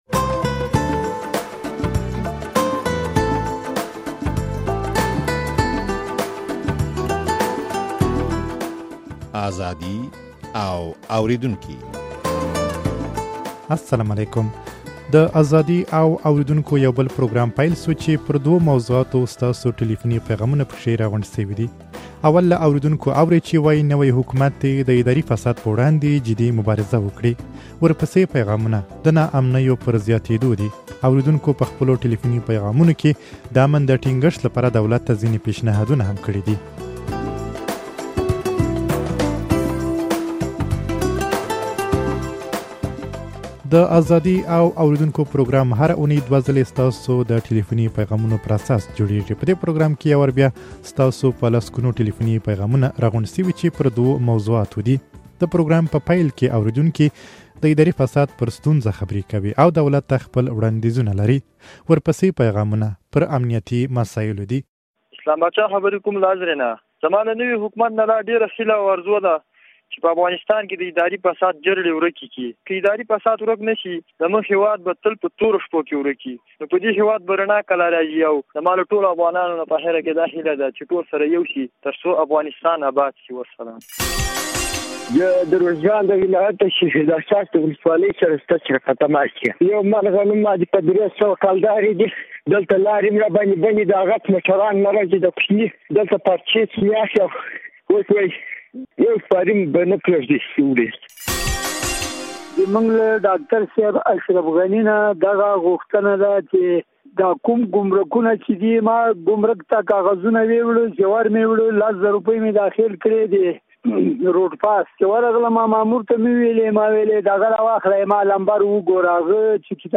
د ازادي او اورېدونکو يو بل پروګرام پيل شو چې پر دوو موضوعاتو ستاسو ټليفوني پيغامونه په کې راغونډ شوي دي.